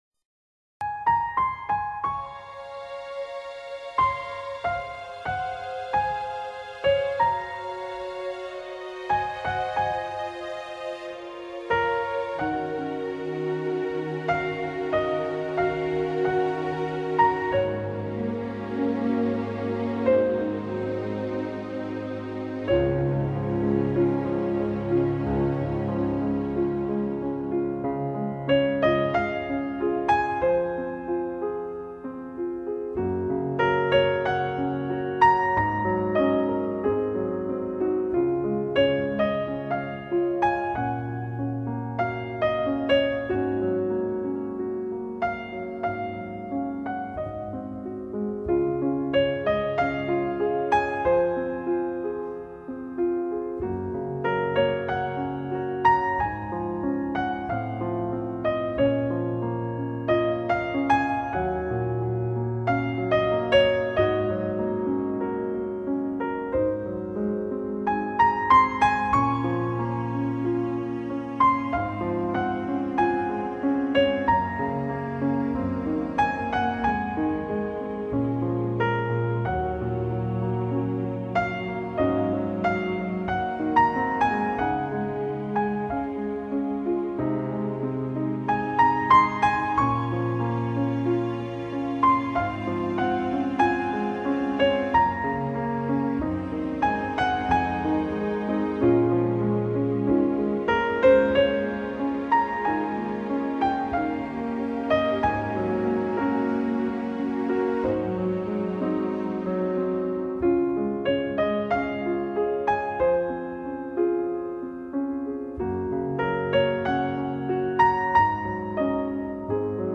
类　别： NewAge
清澈而没有过多的粘稠，温柔却又溪水长流。
更加入柔情的小提琴和单簧管伴奏，听起来非常温暖和舒适。
在清幽的音乐氛围里，清澈的钢琴音色，恰如其分地妆点出绮丽的光影，
融合大提琴与小提琴婉约动人的低诉，形成比例完美的弦乐合奏，